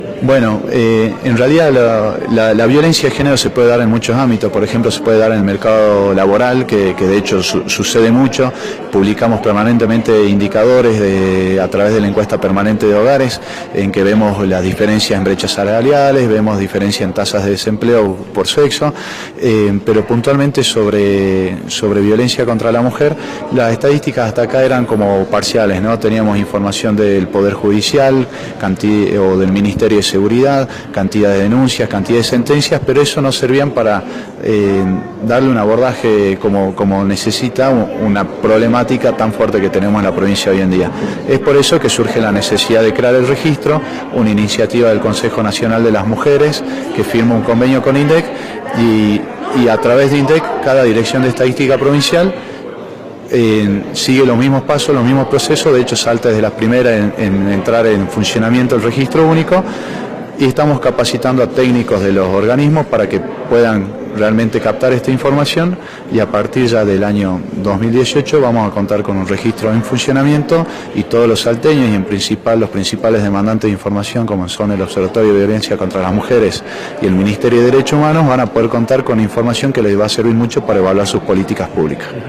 El director general de estadísticas de la provincia, Abel Mendilaharzu, se refirió a la creación del Registro Único de Casos de Violencia contra las Mujeres.